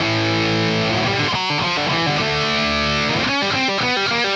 AM_RawkGuitar_110-A.wav